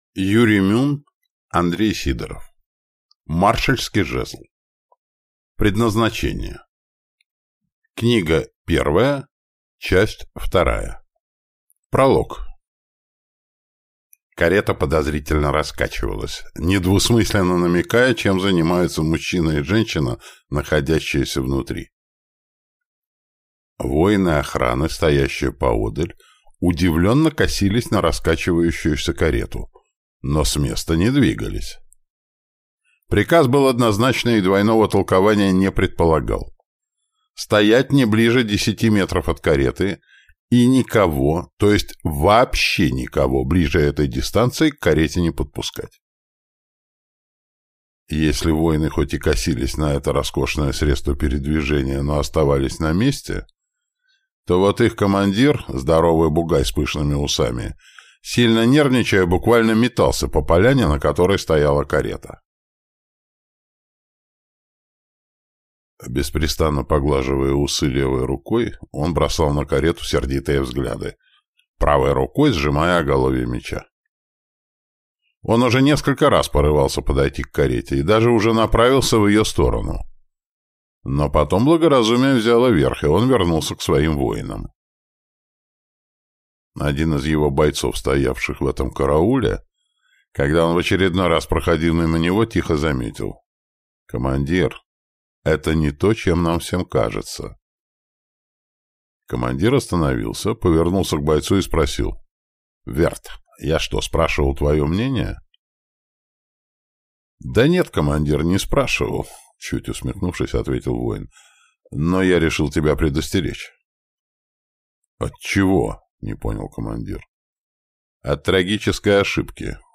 Аудиокнига Предназначение. Книга 1. Часть 2 | Библиотека аудиокниг